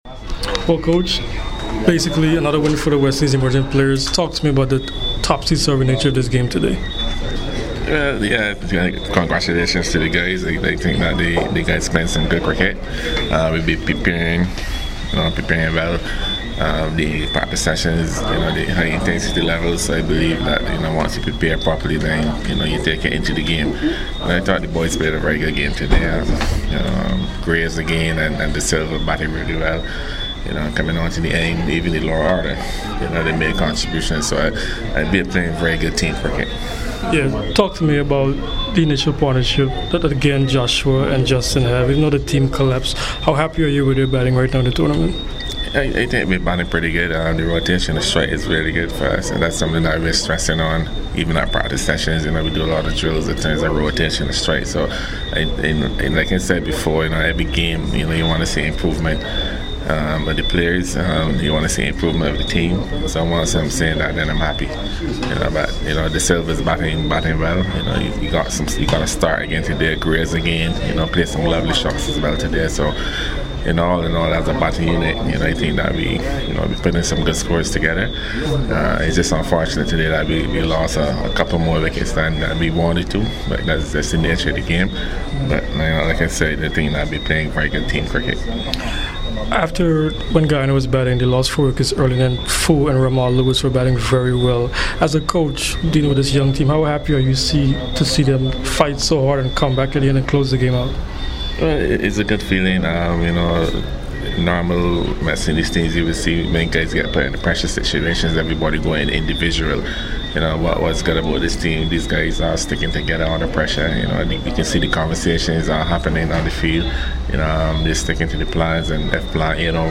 Floyd Reifer spoke to CWI Media after Zone “B” in the Colonial Medical Insurance Super50 Cup on Sunday at Queen's Park Oval and Brian Lara Cricket Academy.